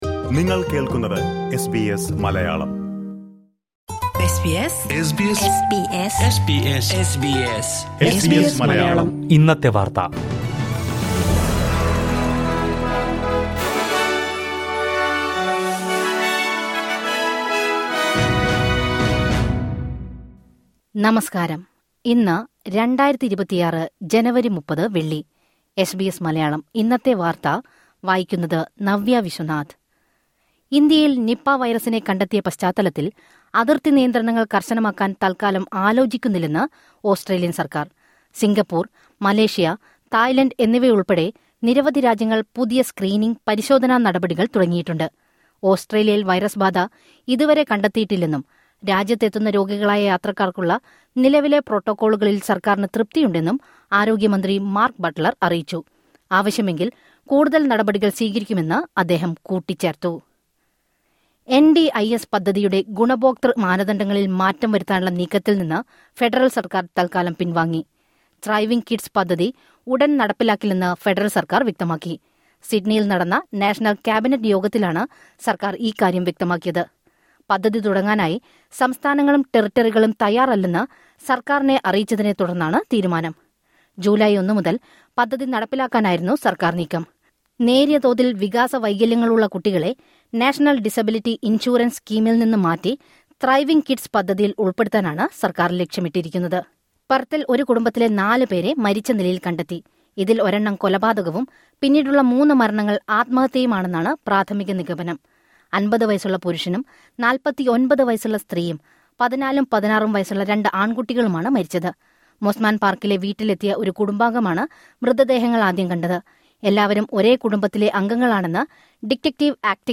2026 ജനുവരി 30ലെ ഓസ്ട്രേലിയയിലെ ഏറ്റവും പ്രധാന വാർത്തകൾ കേൾക്കാം...